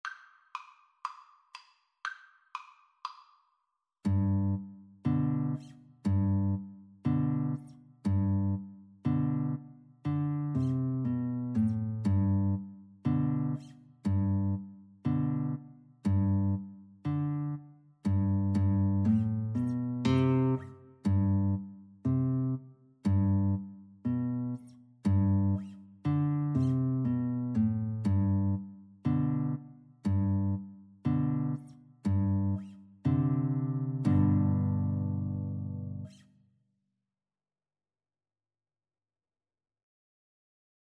A traditional song from the US.
Andante =120
Arrangement for Guitar Duet
G major (Sounding Pitch) (View more G major Music for Guitar Duet )